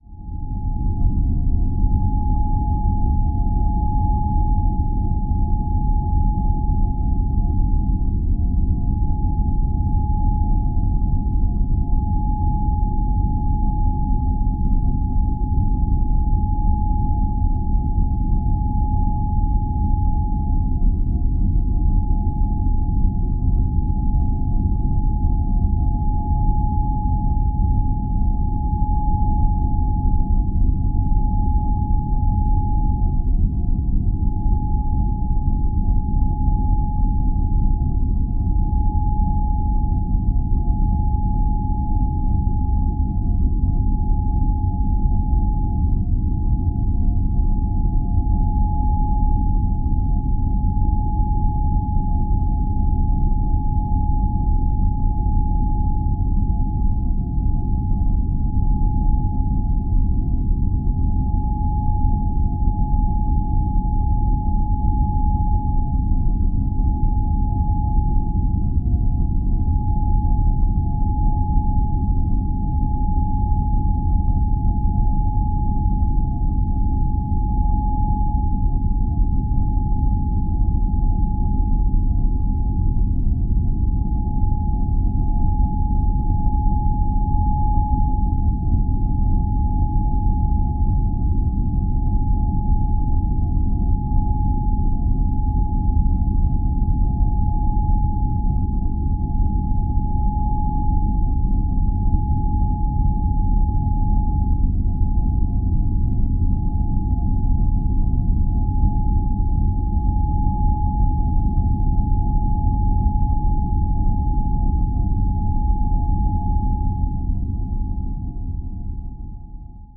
Звук сияющей энергии (атмосферный звук)